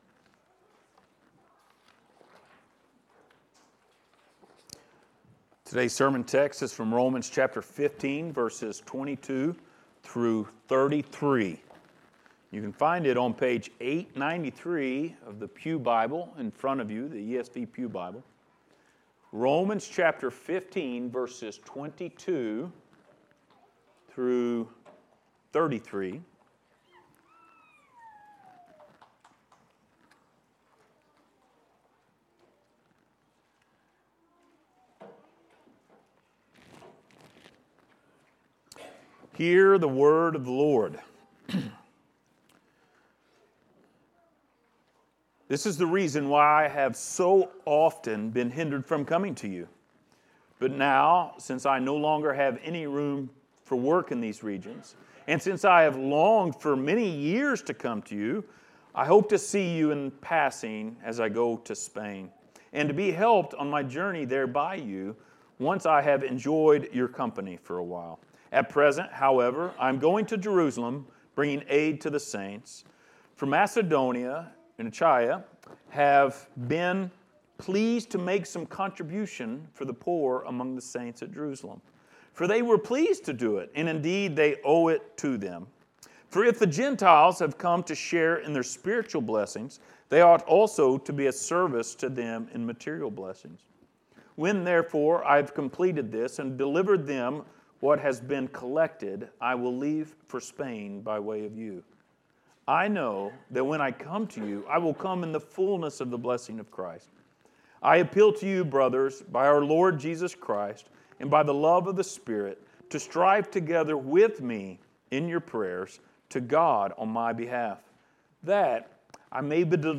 Passage: Romans 15:22-33 Service Type: Sunday Morning